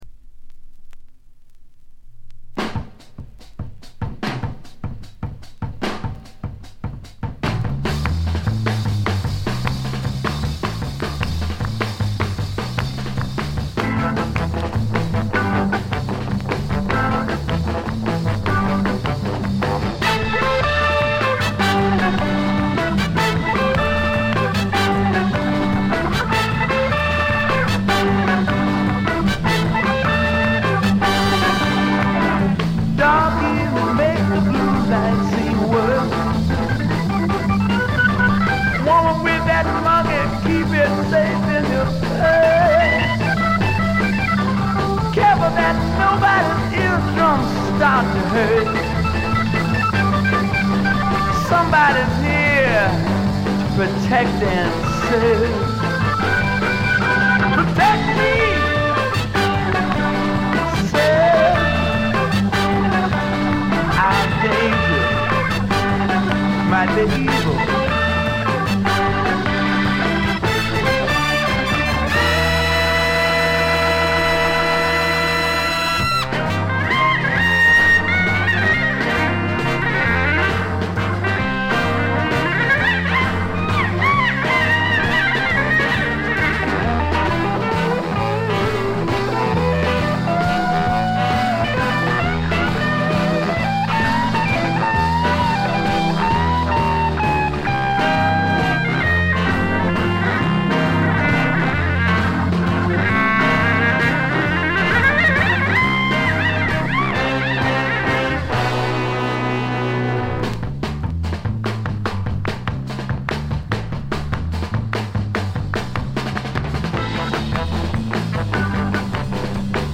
軽微なチリプチ少し。
試聴曲は現品からの取り込み音源です。
Organ, Vocals
Guitar, Electric Sitar
Bass guitar
Tenor Saxophone, Soprano Saxophone, Flute
Percussion